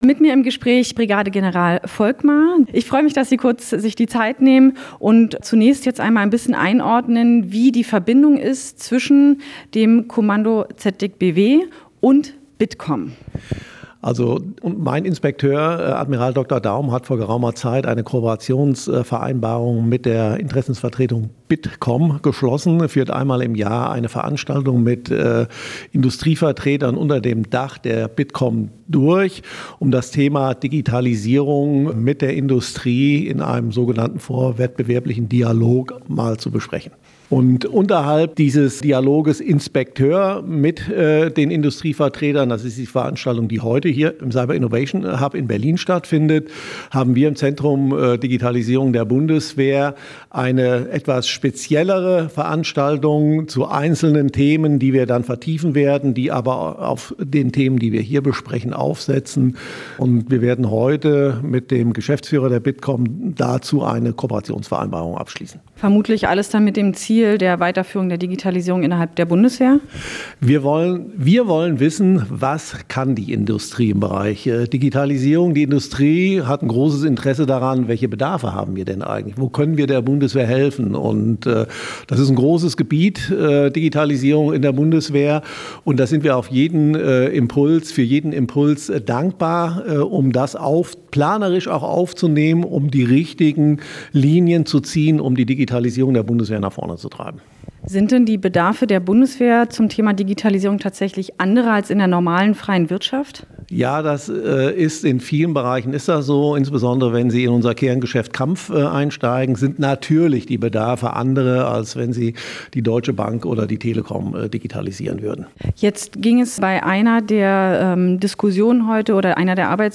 Was das genau bedeutet, erörterte General Volkmer im Interview.
Ein Soldat mit Mikrofon in der Hand spricht zu Zuhörenden.